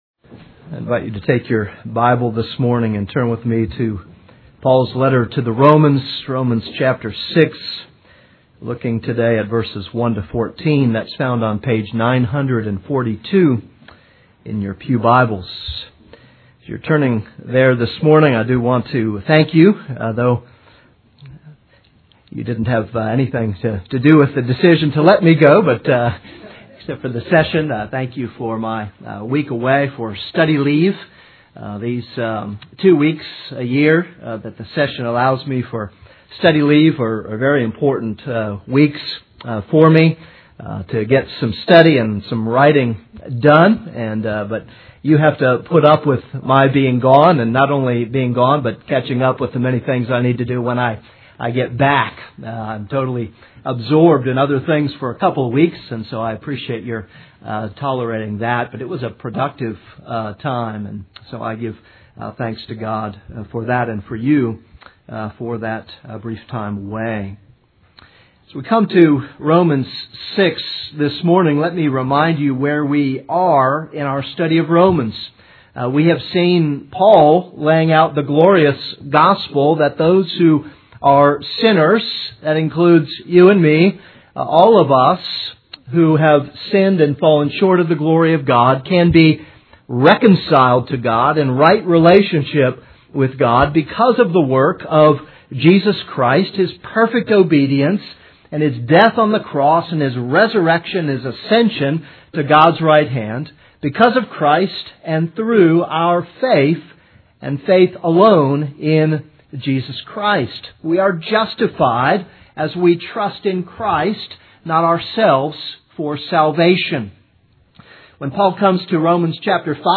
This is a sermon on Romans 6:1-14.